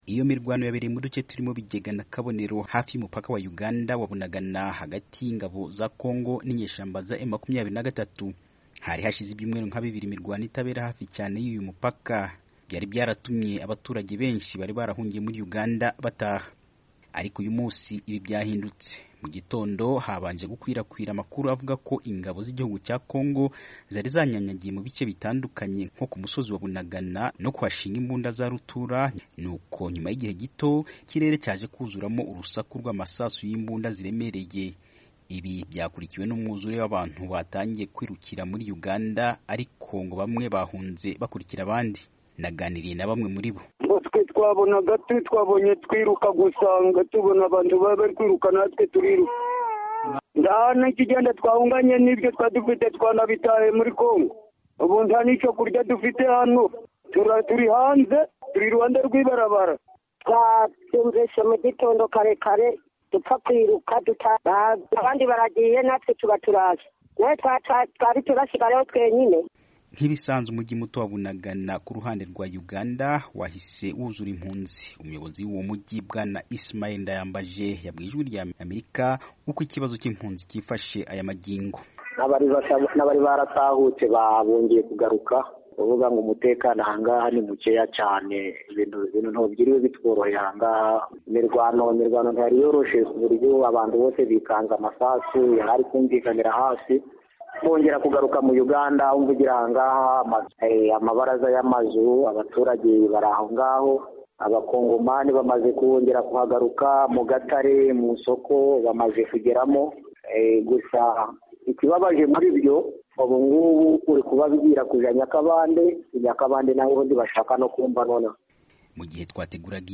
Inkuru y'umunyamakuru w'Ijwi...